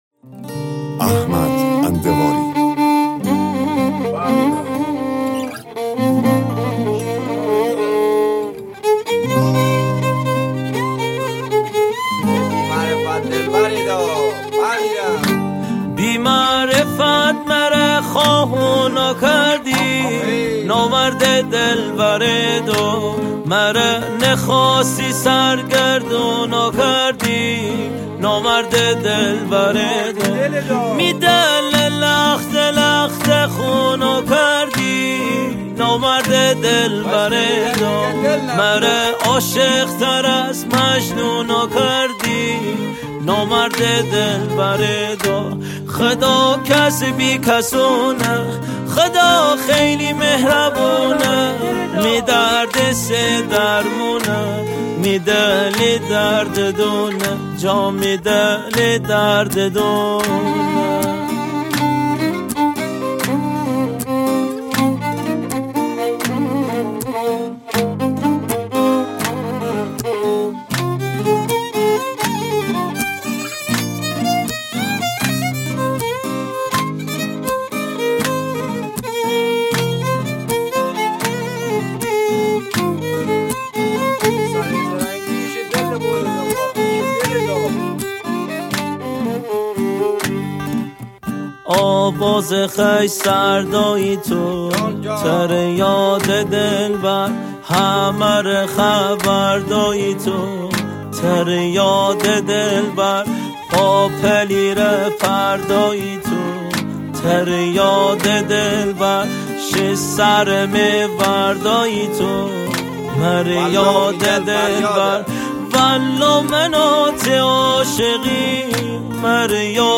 ریمیکس
سبک ریمیکس مازندرانی